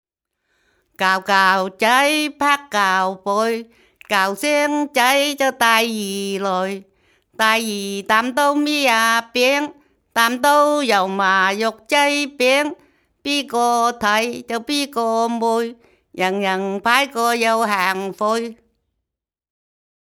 區內一群長者聚在一起，輪流唱著塵封了的古老歌謠，越唱越開懷，不覺間勾勒出昔日農鄉的浮世繪。